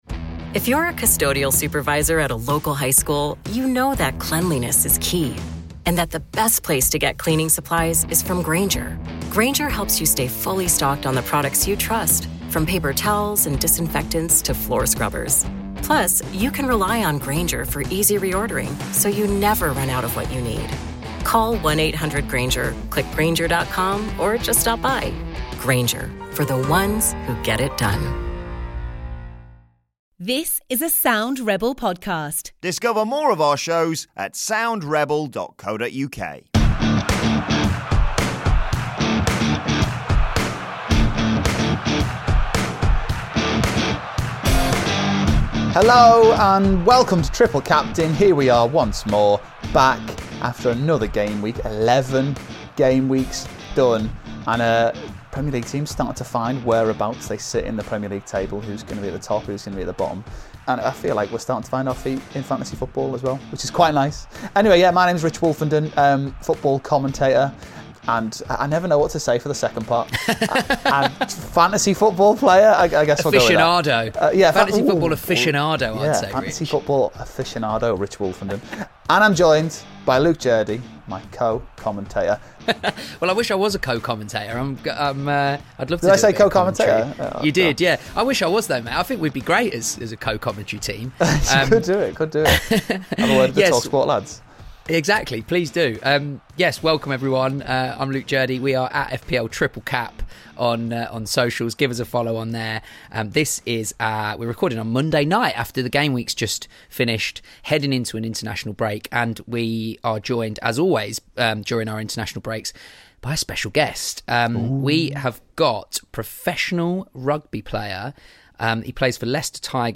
have a more relaxed chat about Fantasy Premier League